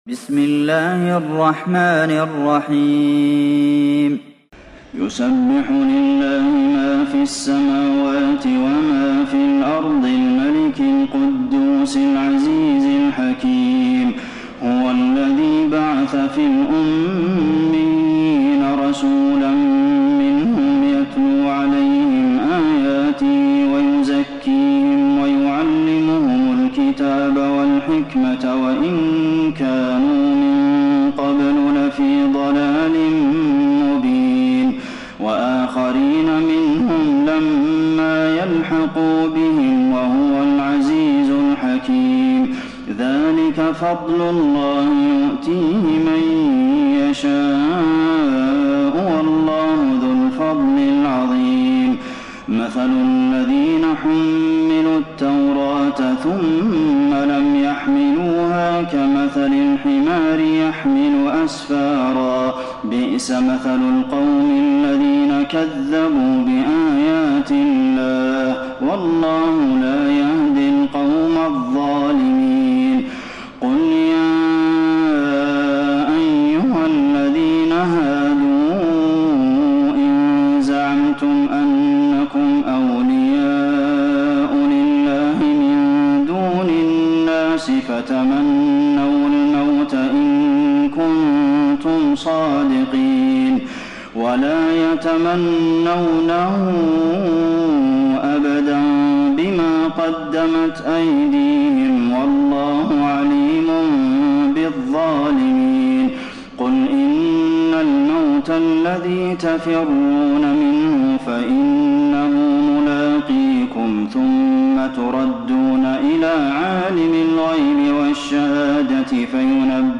تراويح ليلة 27 رمضان 1432هـ من سورة الجمعة الى التحريم Taraweeh 27 st night Ramadan 1432H from Surah Al-Jumu'a to At-Tahrim > تراويح الحرم النبوي عام 1432 🕌 > التراويح - تلاوات الحرمين